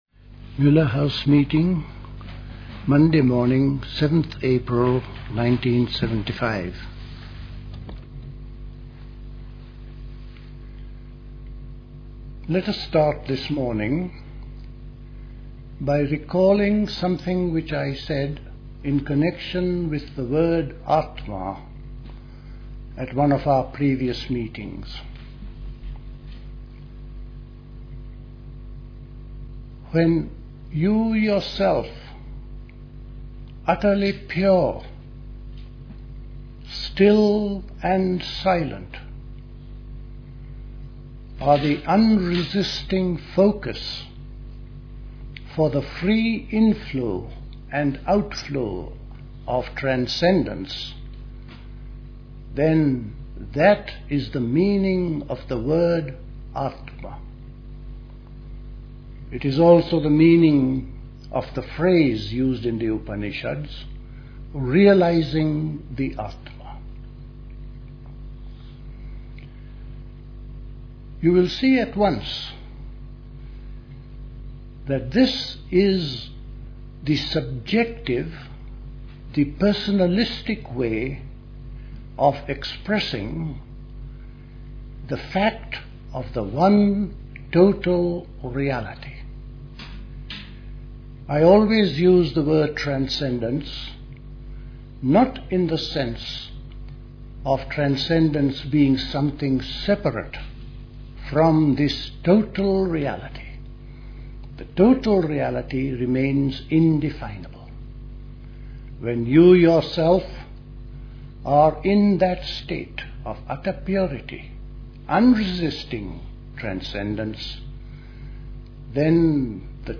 Recorded at the 1975 Elmau Spring School.